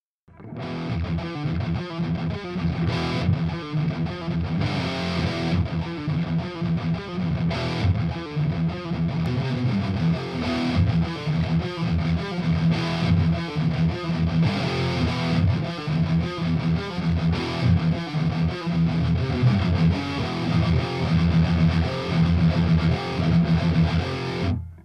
meme son que la dual si ce n'ai un mode en moins (rectifiage à tube, que les amateurs de gros son n'utilisent pas de toute facon)
enregistré rapidos (pas travaillé) mais pour montrer que le recto peut etre précis dans les rythmiques rapides (même si mon jeu de guitare ne l'est pas extrêmement)